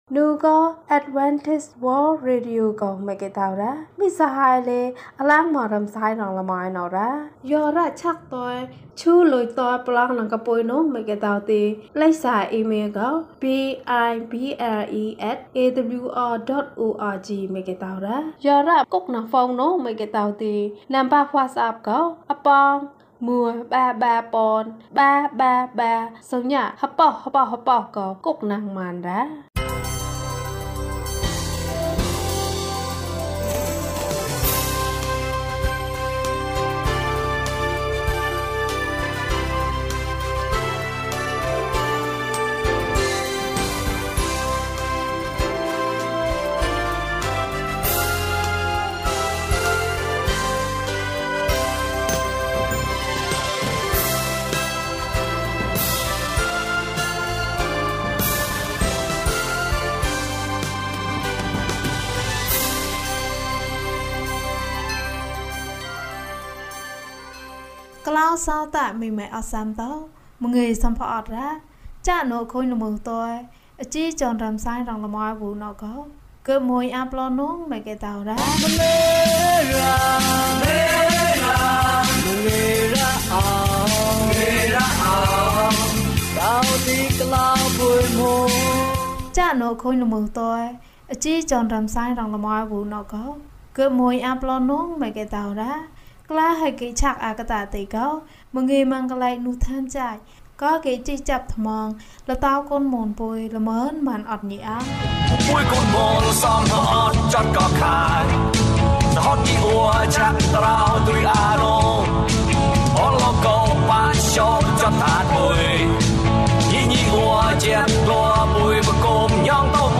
ဘုရားသခင်ထံမှကောင်းကြီးမင်္ဂလာ။၂၈ ကျန်းမာခြင်းအကြောင်းအရာ။ ဓမ္မသီချင်း။ တရားဒေသနာ။